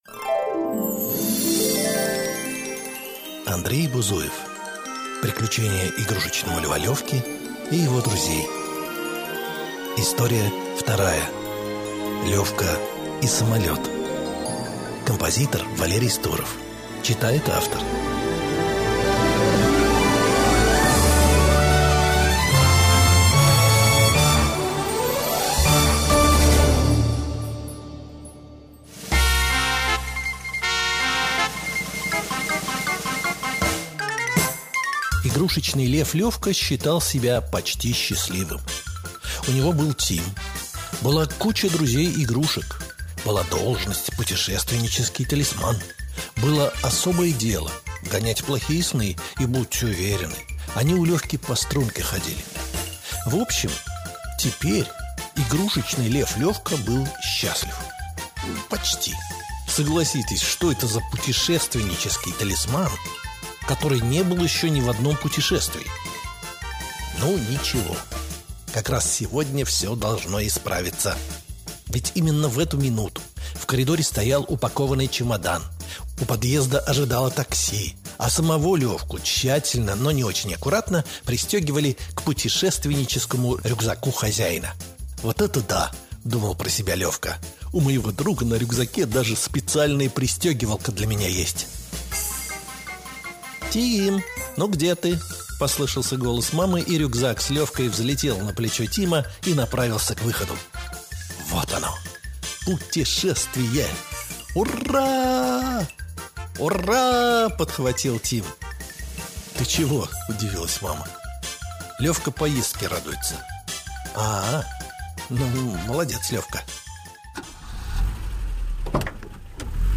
Аудиокнига Приключения игрушечного льва Лёвки. История вторая | Библиотека аудиокниг